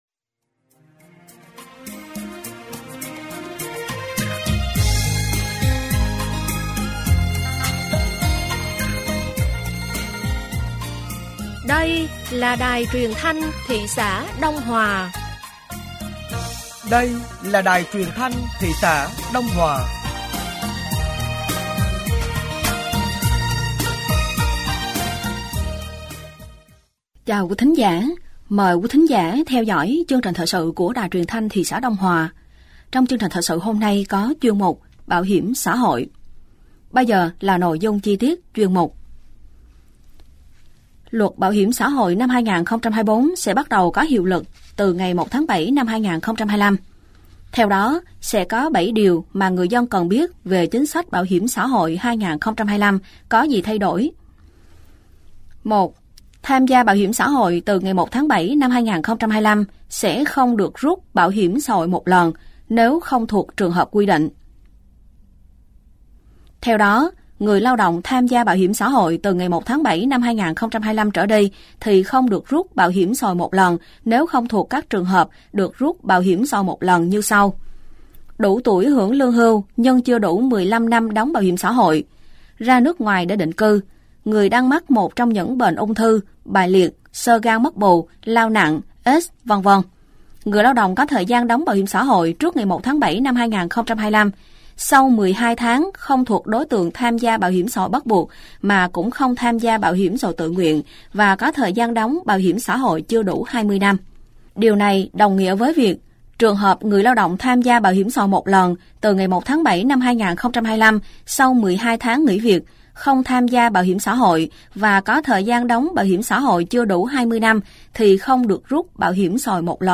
Thời sự tối ngày 29 và sáng ngày 30 tháng 3 năm 2025